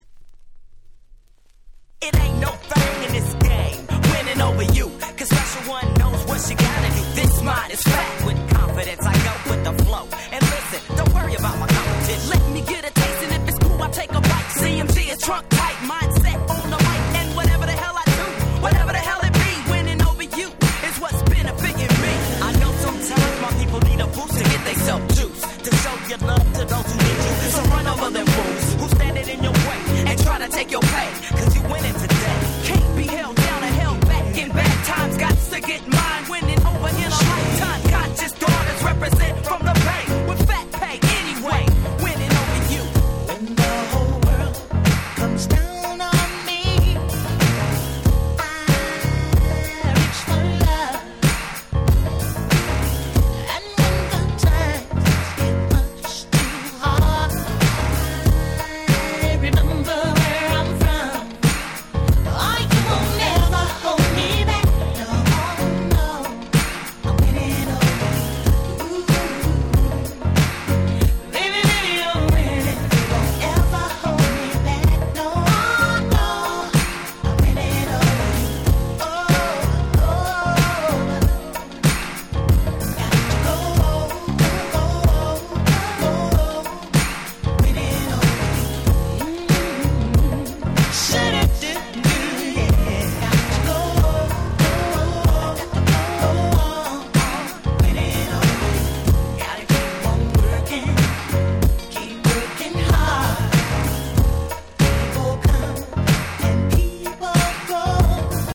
94' Nice R&B/Hip Hop Soul !!